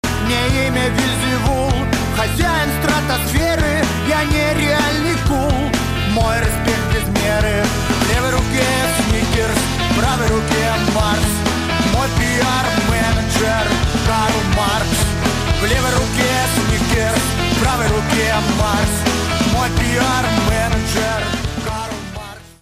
• Качество: 128, Stereo
панк-рок
ска
ска-панк